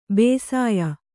♪ bēsāya